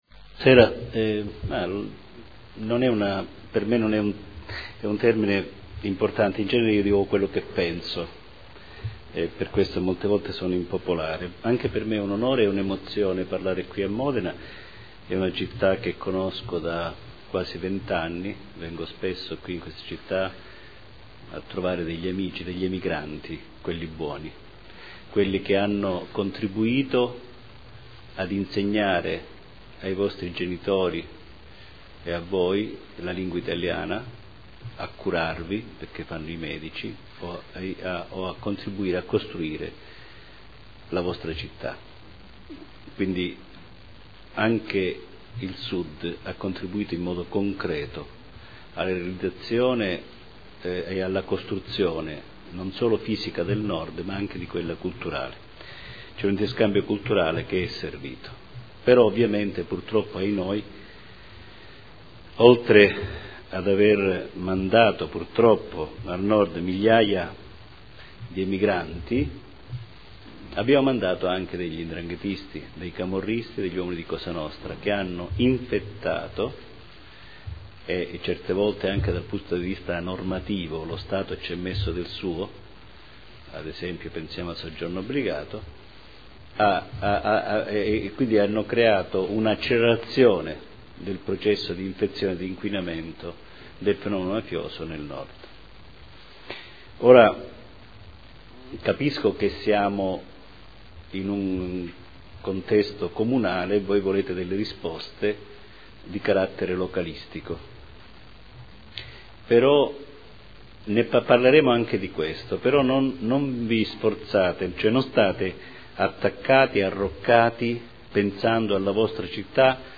Nicola Gratteri — Sito Audio Consiglio Comunale